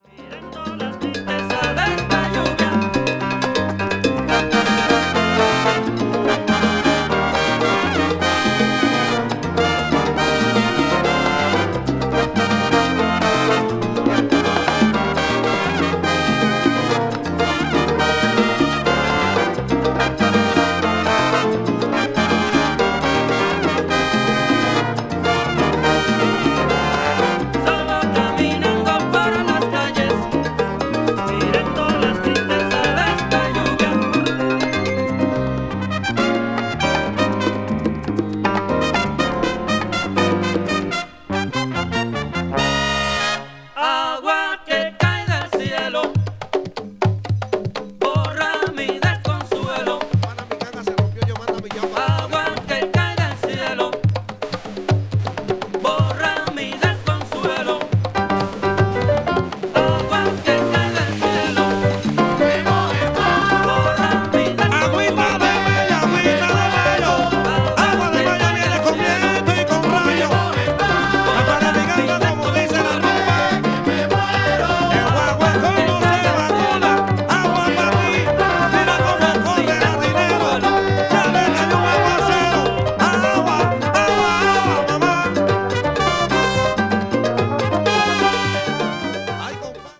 media : VG+/VG+(A1/B1:盤焼けによる薄い軽いバックグラウンドノイズが入る箇所あり)